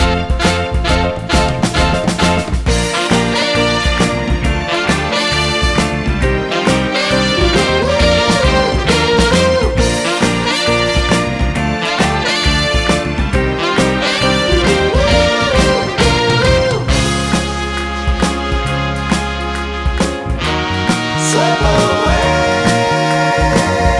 no Backing Vocals Soul / Motown 2:09 Buy £1.50